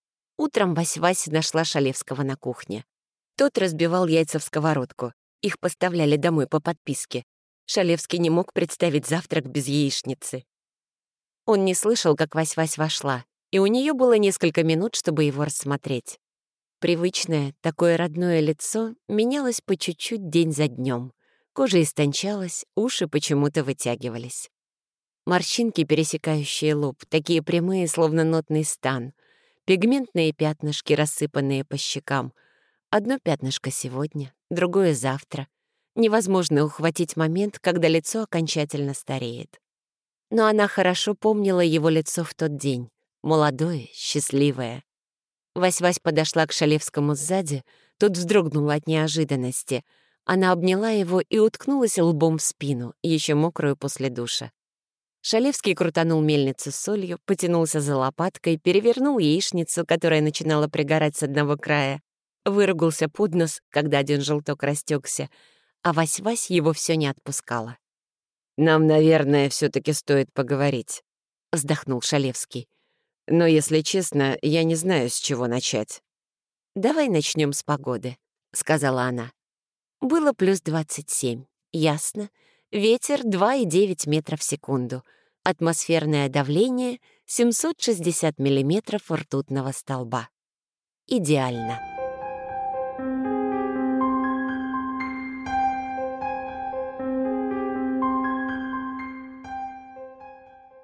Жен, Аудиокнига/Средний
Микрофон SCARLETT CM25 Звуковая карта FOCUSRITE Дикторская кабина